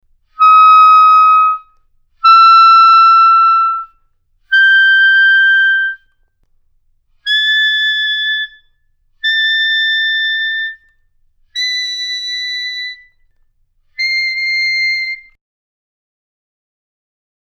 The longer tube length that is in play produces thicker, darker timbres that are generally more secure at loud dynamic levels (Example #36).